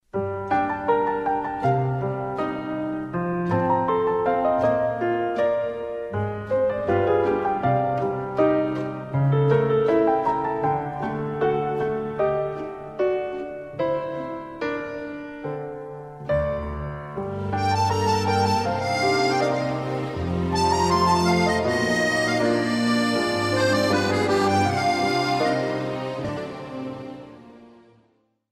Очень красивая мелодия смс на звонок